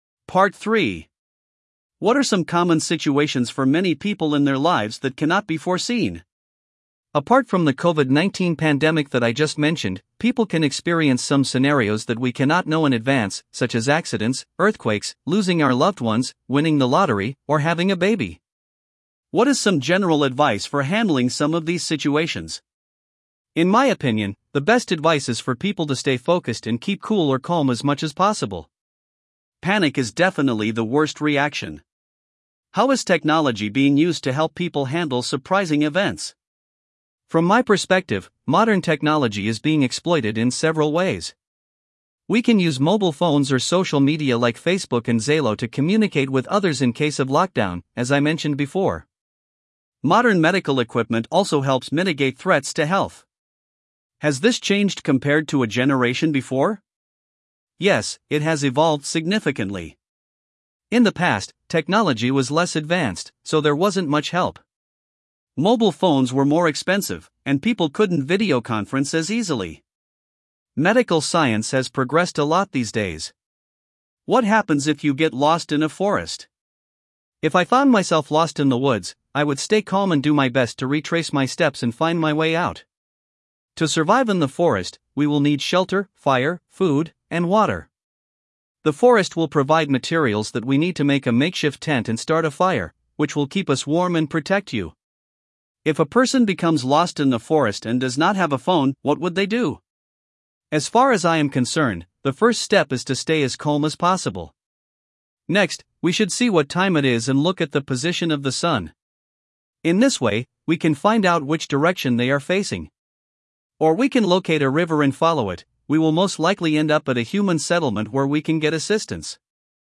Guy (English US)